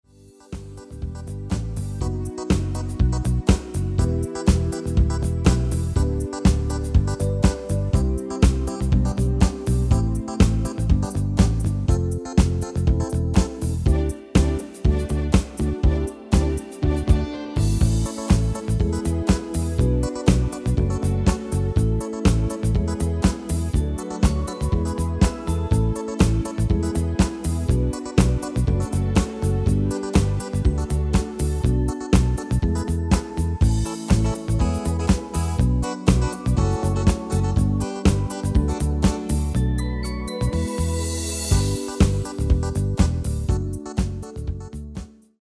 Demo/Koop midifile
Genre: Nederlandse artiesten pop / rock
Toonsoort: G
- Géén vocal harmony tracks
Demo's zijn eigen opnames van onze digitale arrangementen.